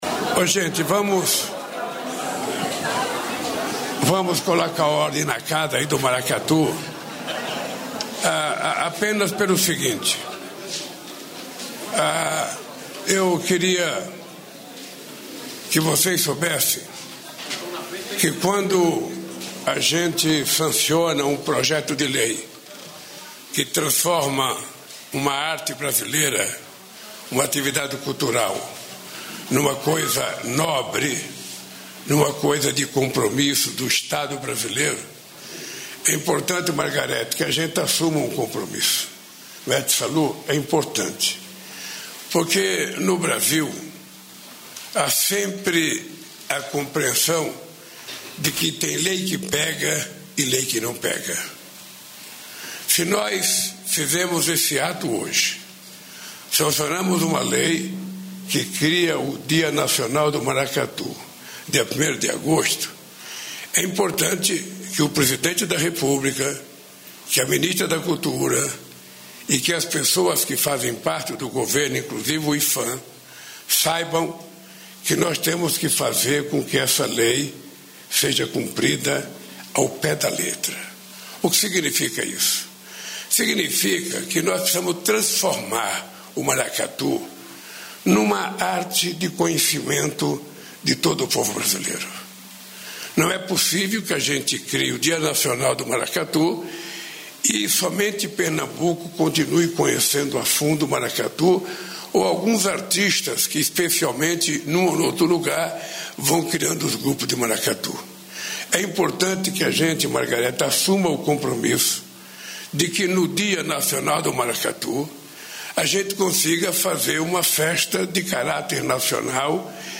Manifestação cultural tradicional de Pernambuco será comemorada anualmente no dia 1º de agosto em todo o território nacional; ouça a íntegra do discurso do presidente Luiz Inácio Lula da Silva, nesta terça-feira (12), no Palácio do Planalto, após a sanção do Projeto de Lei.